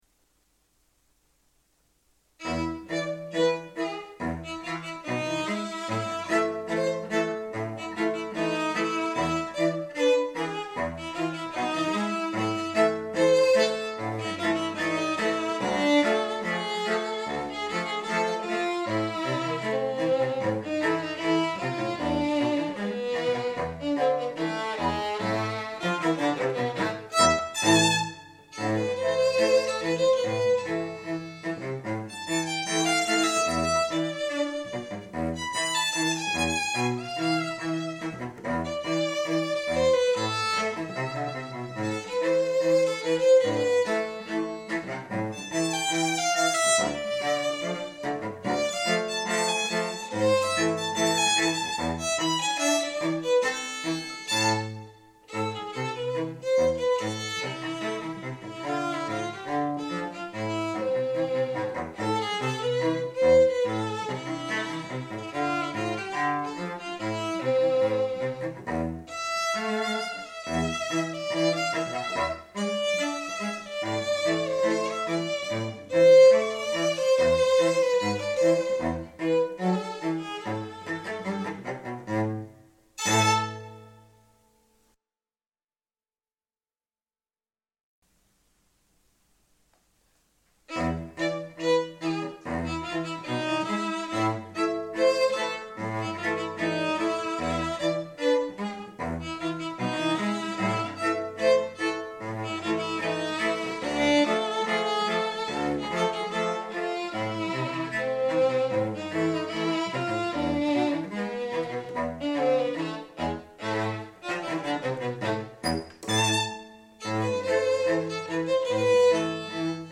VIOLIN & CELLO DUO